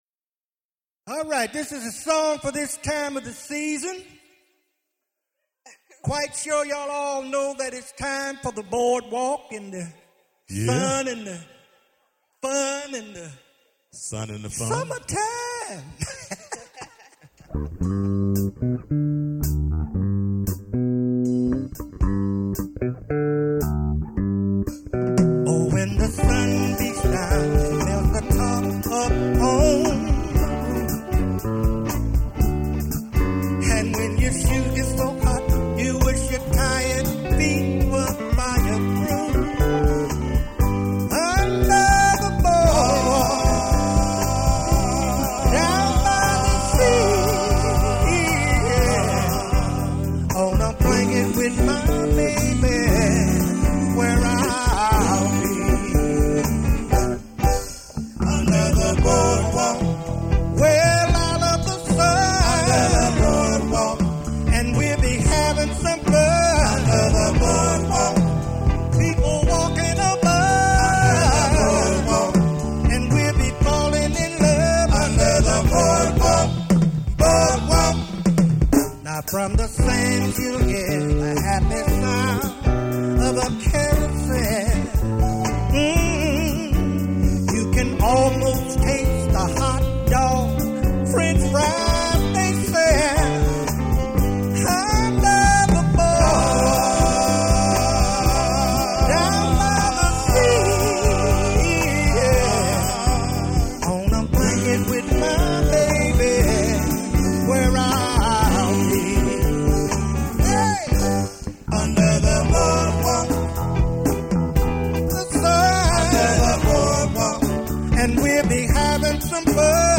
singing group